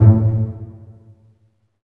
STR PIZZ.07R.wav